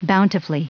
Prononciation du mot bountifully en anglais (fichier audio)
Prononciation du mot : bountifully